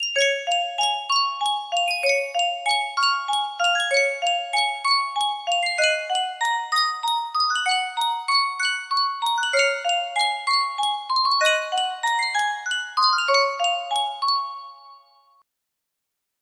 Yunsheng Music Box - Johann Strauss II Artist's Life 4317 music box melody
Full range 60